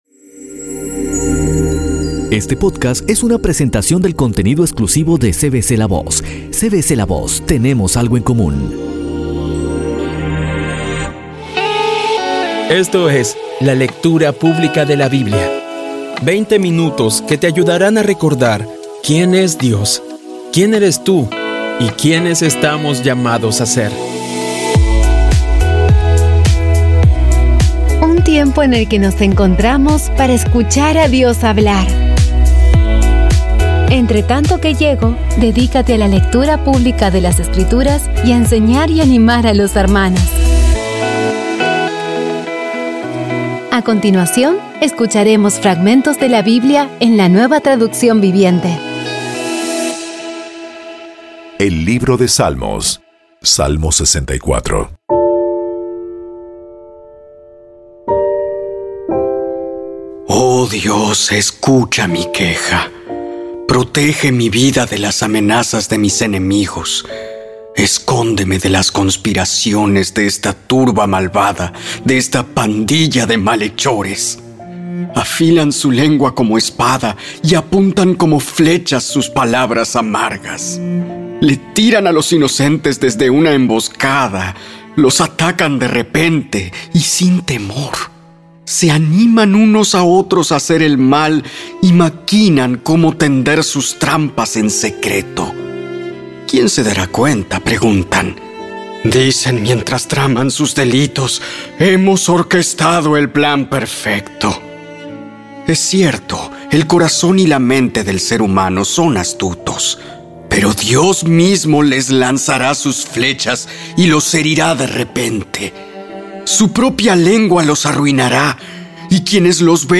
Audio Biblia Dramatizada Episodio 139
Poco a poco y con las maravillosas voces actuadas de los protagonistas vas degustando las palabras de esa guía que Dios nos dio.